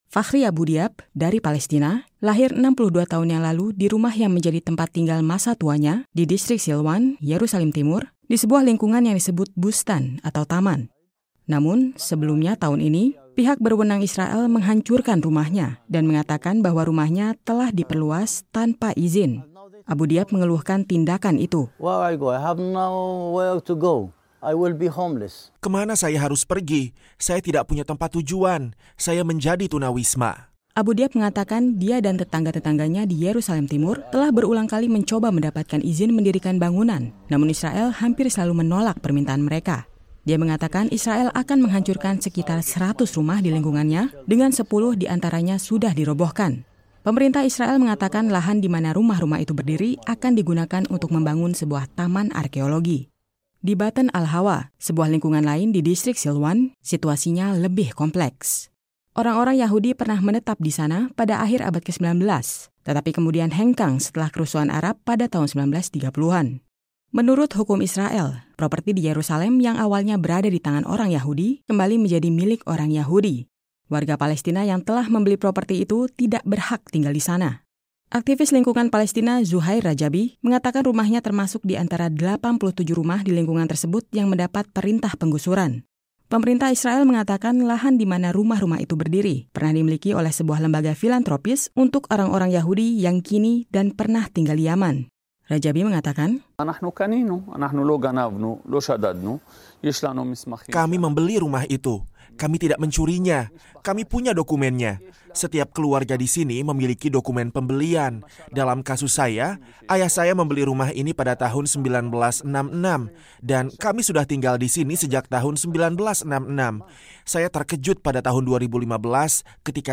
Yerusalem (VOA) —